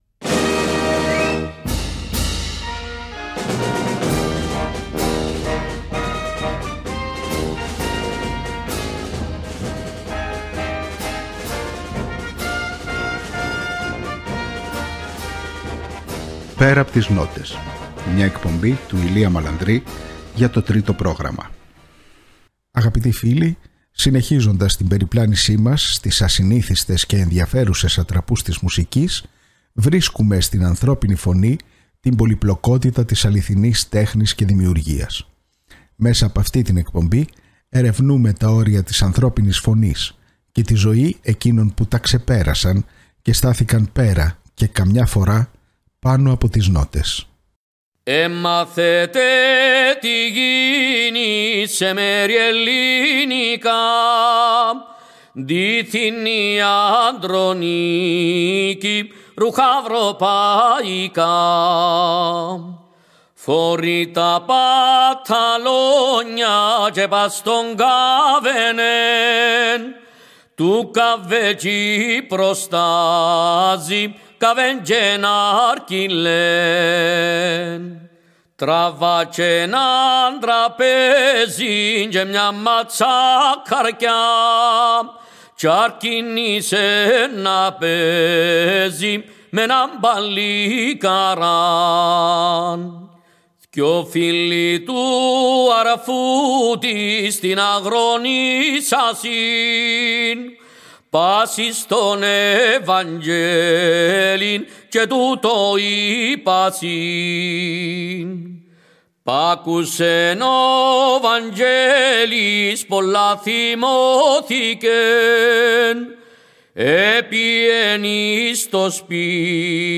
Μέσα από σπάνιο ηχητικό αρχείο συνεντεύξεων και άγνωστων ανέκδοτων ηχογραφήσεων ξετυλίγονται τα Πορτραίτα 30 καλλιτεχνών που άφησαν ένα τόσο ηχηρό στίγμα στην τέχνη καταφέρνοντας να γίνουν σημείο αναφοράς και να εγγραφούν στην ιστορική μνήμη, όχι μόνο ως ερμηνευτές αλλά και ως σύμβολα.